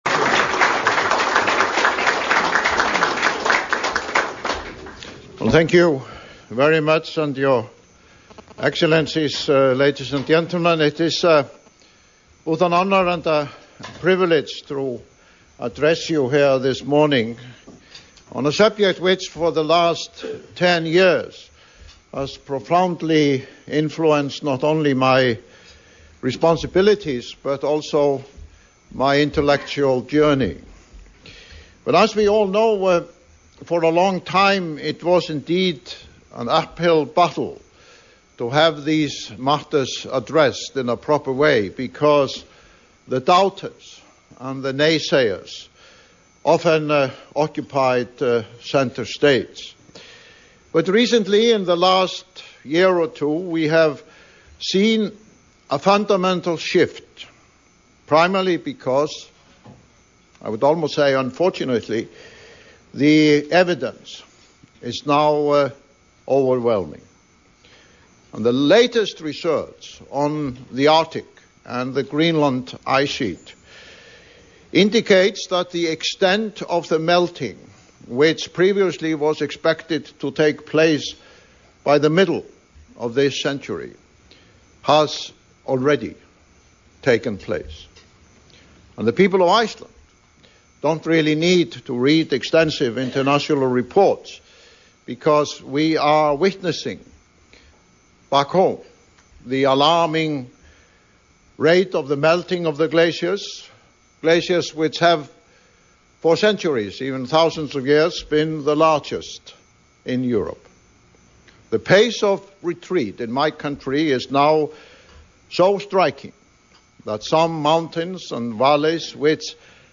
Forseti flutti fyrirlestur á vegum Carnegie Council í New York, en sú stofnun er virtur alþjóðlegur vettvangur. Fyrirlesturinn sóttu sendiherrar ríkja hjá Sameinuðu þjóðunum og fjölmargir sérfræðingar og áhrifamenn í alþjóðamálum.